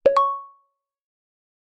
Звуки уведомления чата